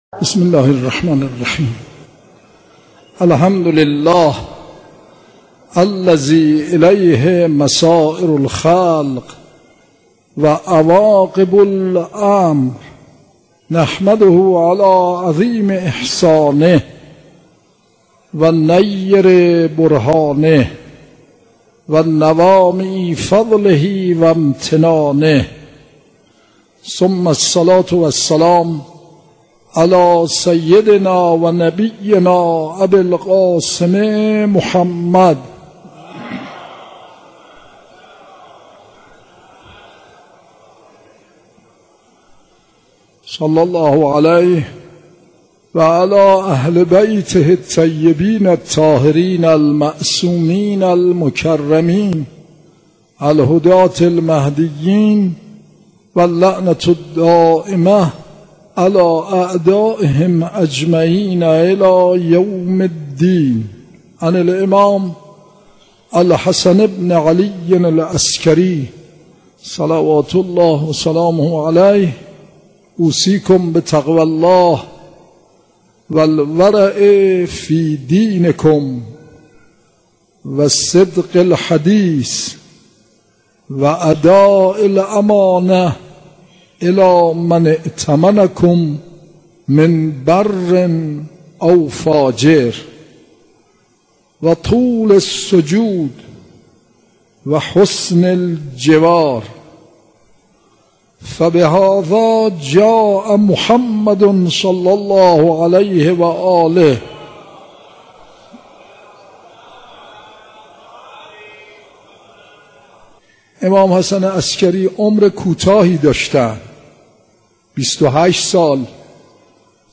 آوای معرفت | سخنرانی